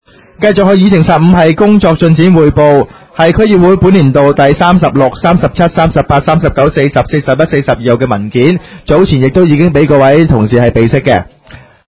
区议会大会的录音记录
油尖旺区议会会议室